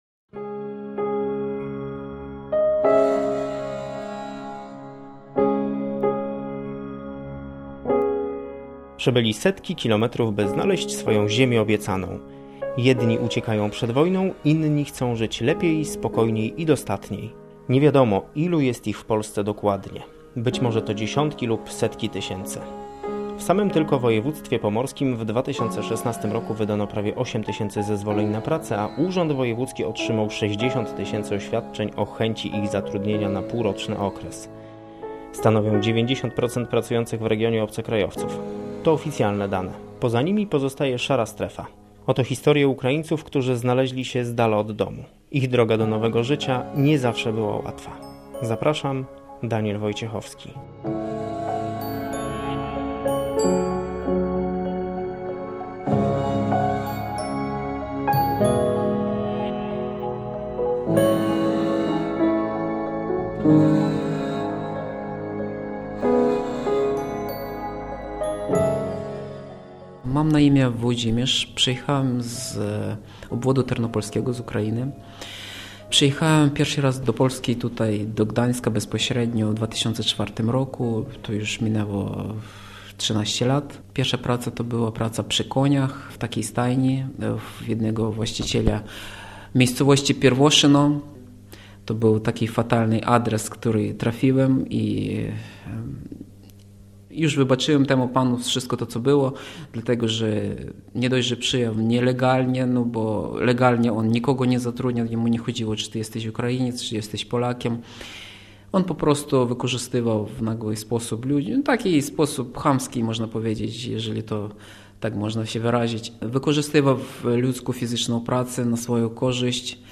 Reportaż „Ziemia Obiecana”